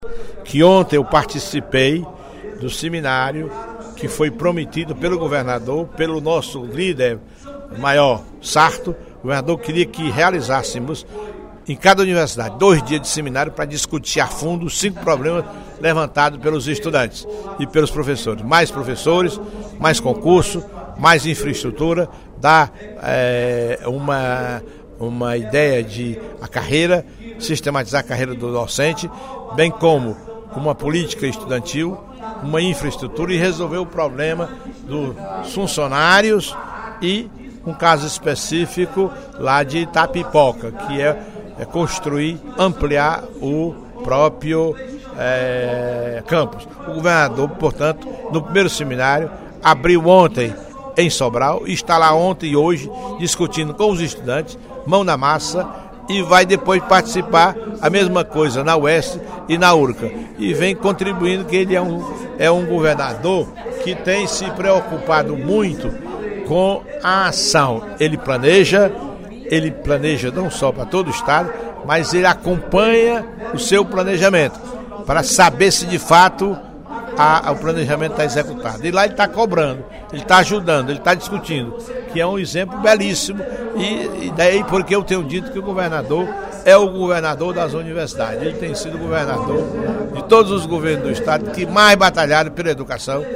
Durante o primeiro expediente da sessão plenária da Assembleia Legislativa desta quarta-feira (05/02), o deputado Professor Teodoro (PSD) comemorou a iniciativa do Governo do Ceará de realizar seminário junto às universidades estaduais para tratar de demandas relacionadas aos alunos, professores e servidores.